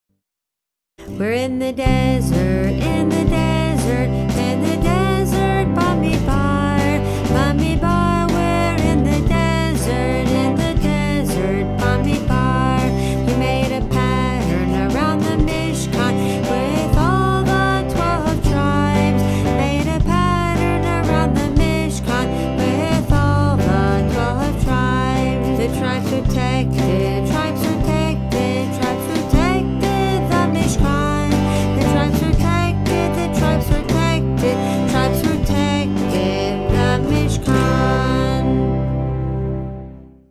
1) SONG:  (tune: Oh My Darling Clementine)